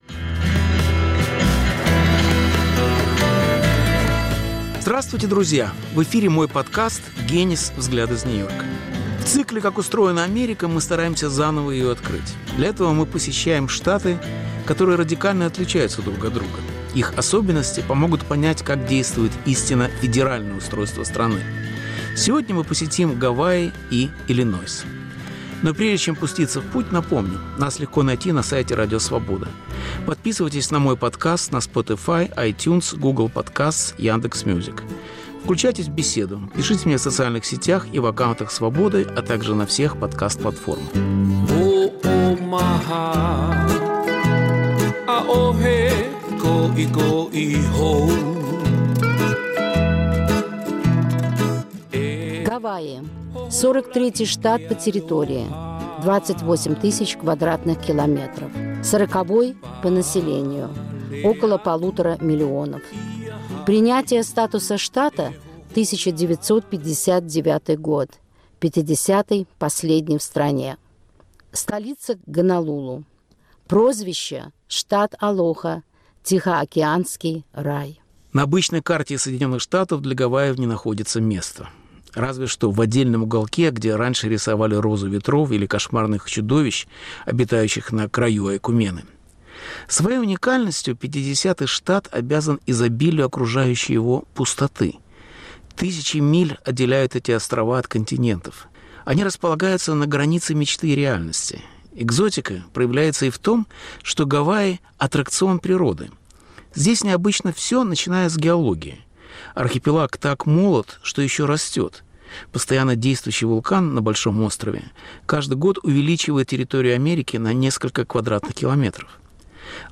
Александр Генис приглашает посетить сказочные острова и самое сердце Среднего Запада. Повтор эфира от 05 ноября 2023 года.